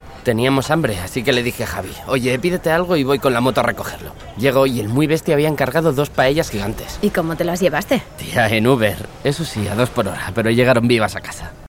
sehr variabel
Jung (18-30)
Eigene Sprecherkabine
Commercial (Werbung)